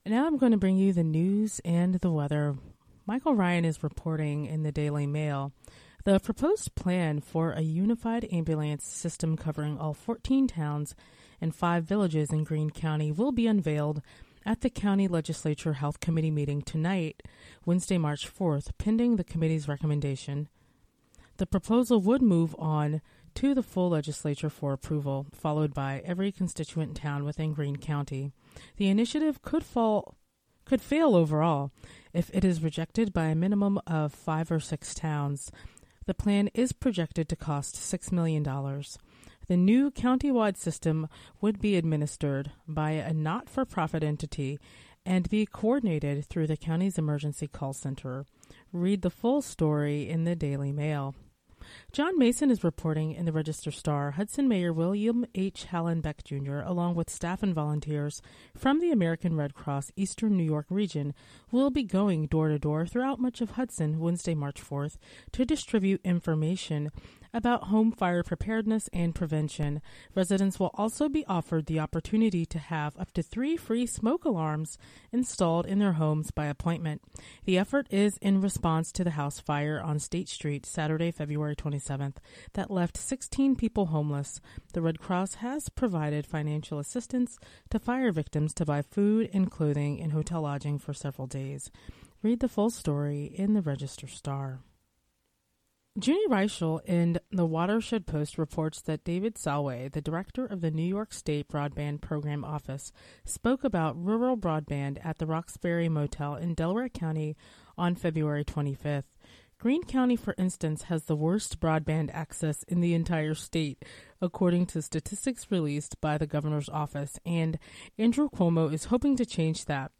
Local news and weather for Wednesday, March 4, 2015.